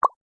ui play.mp3